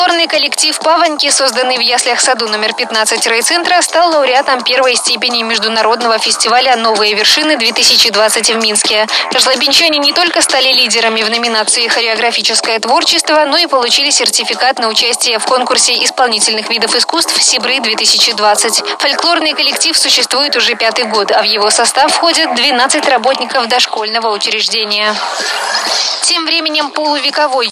ГОМЕЛЬ_ФМ_радио.m4a